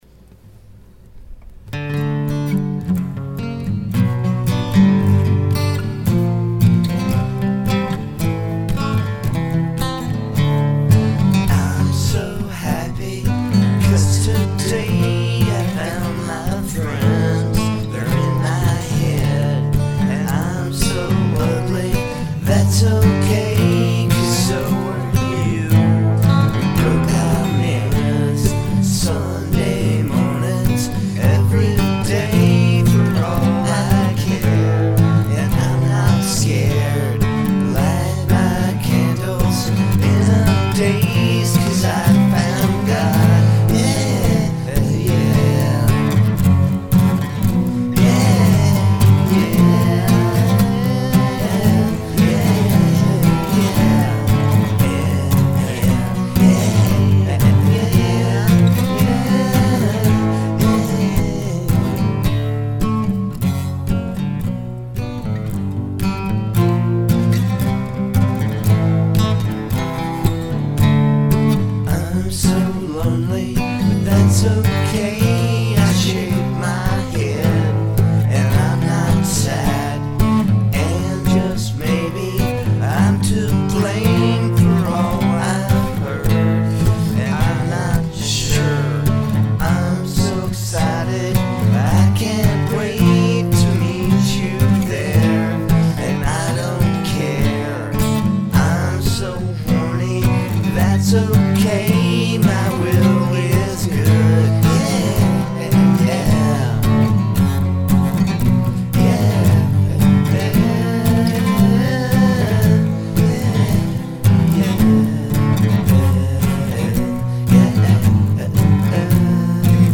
It is difficult to play thrash on an acoustic guitar.